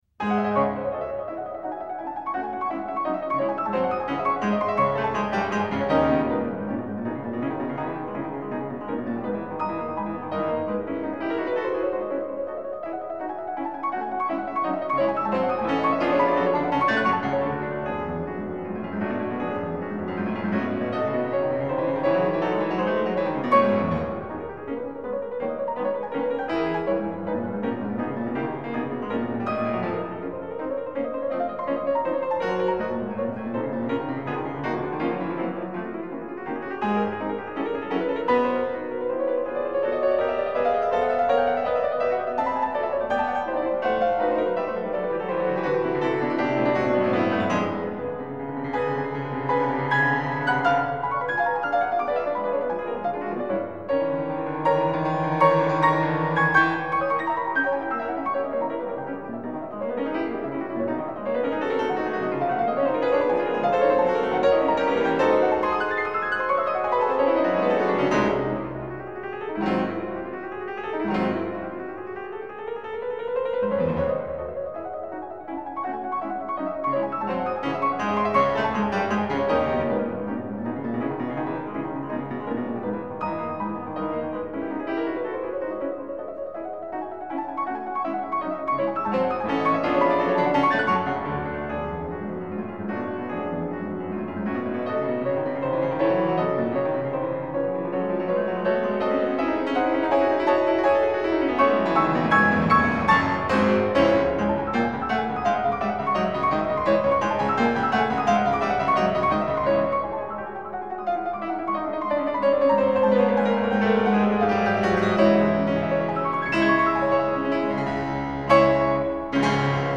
钢琴演奏
唱片制式：DDD